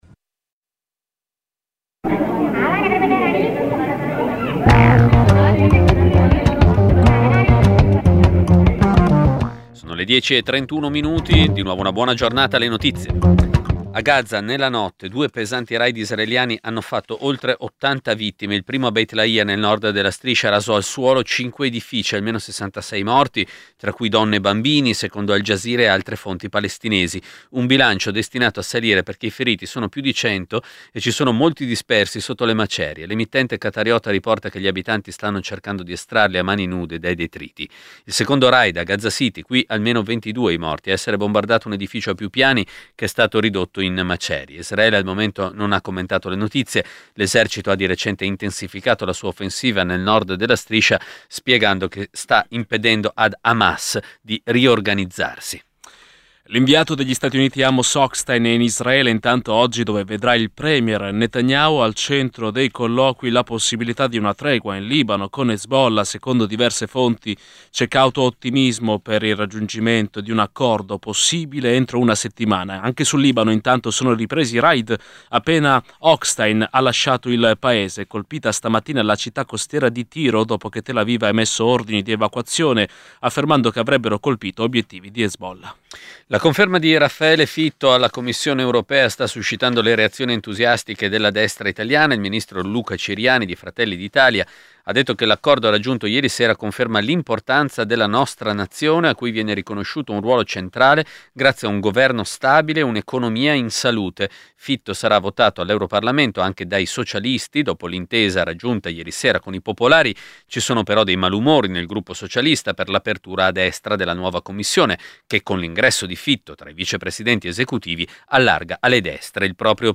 Giornale radio nazionale - del 21/11/2024 ore 10:30